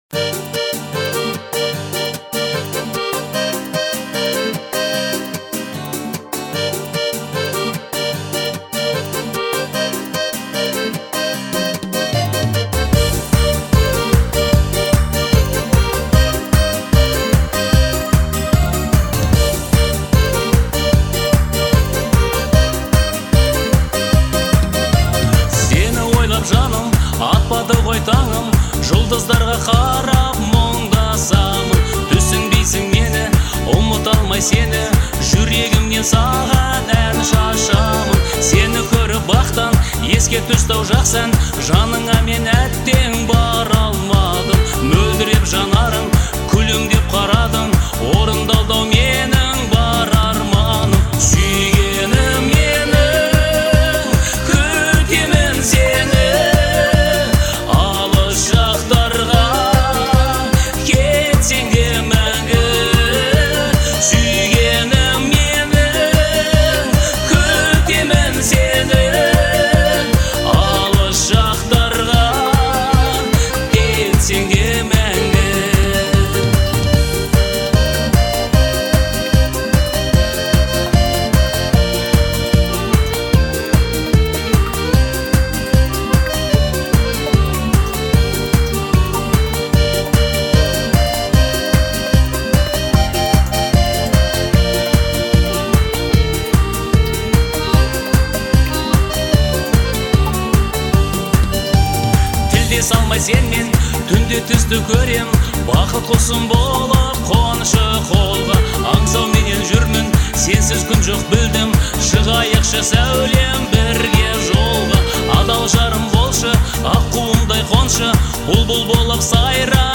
это трек в жанре казахского поп